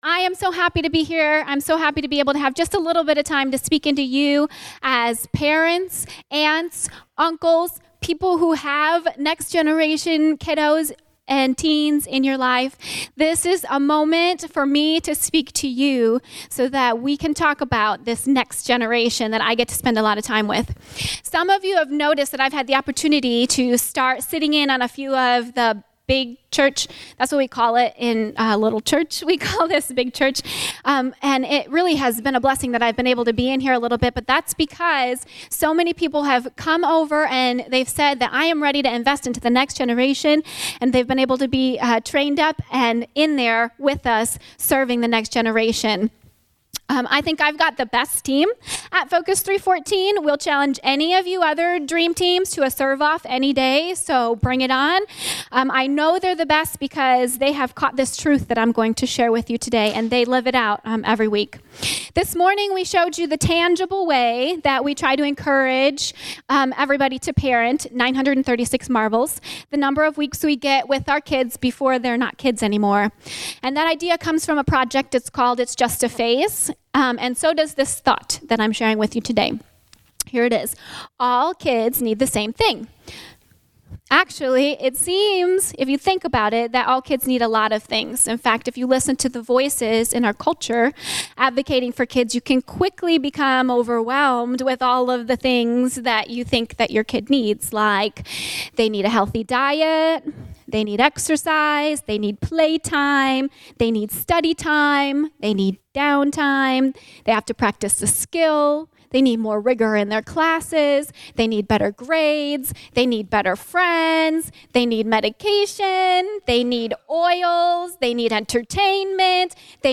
Enjoy this special Move Up service as we celebrate all of the children in our church and give special attention to those who are moving up to the next level of our Move Kids program.